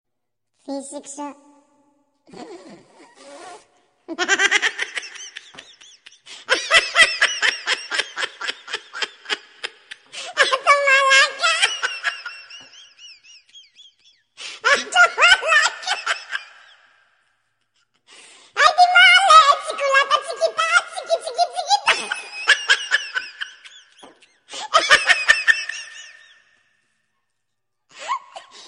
Description: Funny laugh